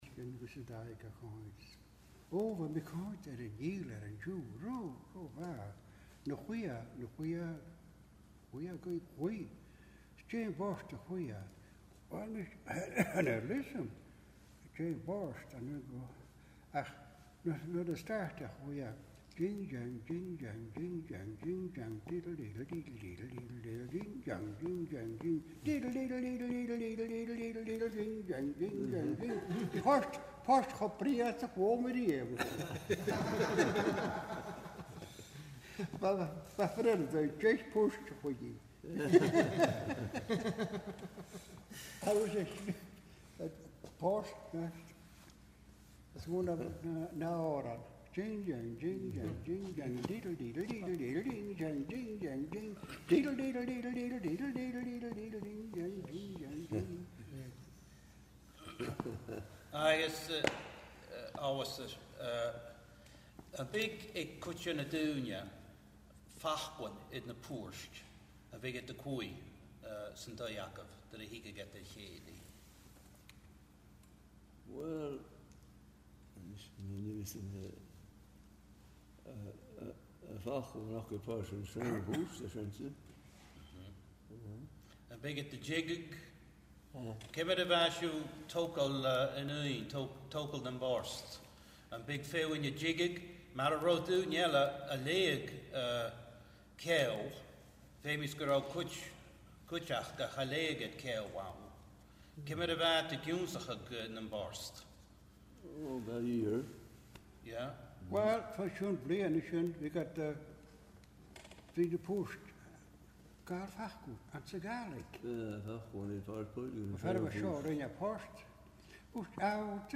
Glenora Distillery, An Gleann Dubh
Agallamh